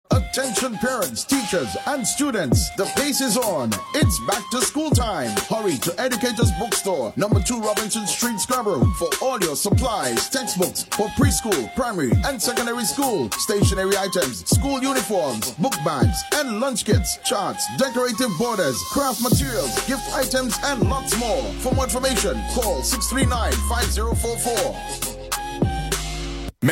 Top 5 Radio Ads
In the fifth position of Top Ads for Back-to-School, Educators Book Store, Tobago holds this spot with their detailed voice over 30 second Ad.